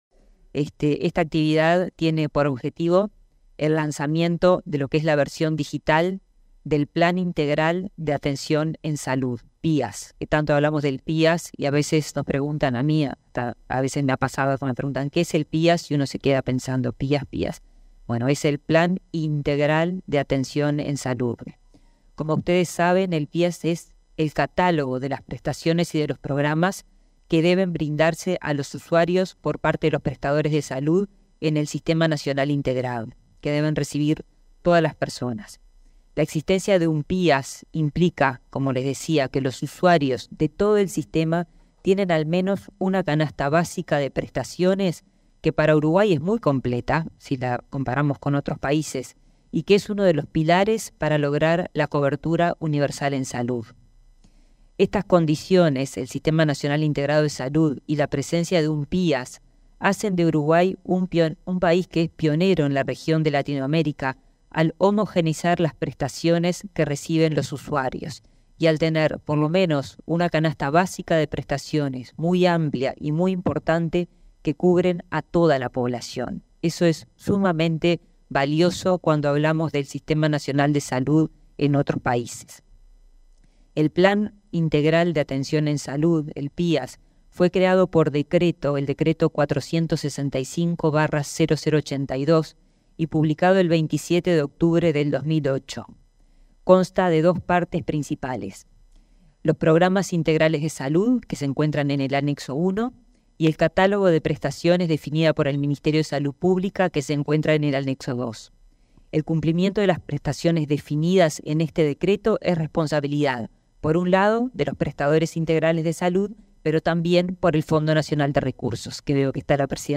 Acto de lanzamiento de la versión digital del PIAS
Declaraciones de la ministra de Salud Pública, Karina Rando